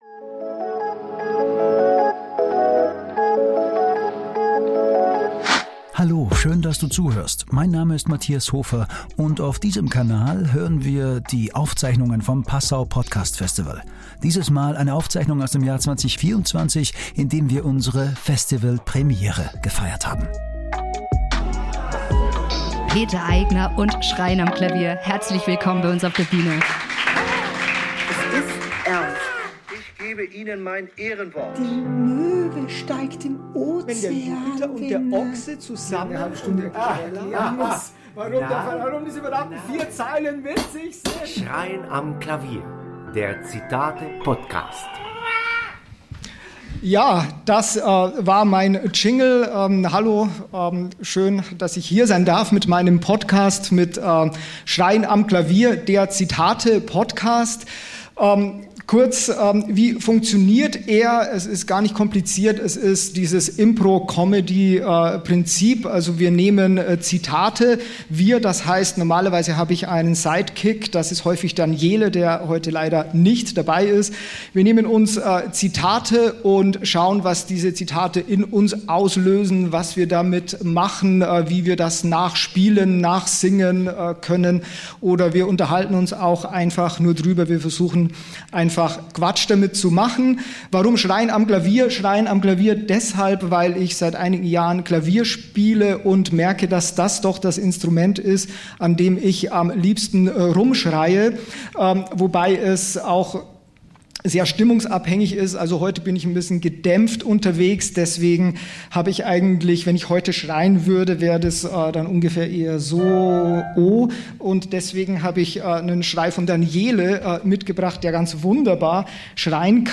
Beschreibung vor 3 Monaten Live auf der Bühne im Kulturmodell Bräugasse beim Festival 2024: Schreien am Klavier, der Zitate-Podcast, ist eine humoristische Auseinandersetzung mit Zitaten aller Art.
Dazu gibt’s Schreie am Klavier.
Meistens hat er einen oder mehrere Gäste dabei.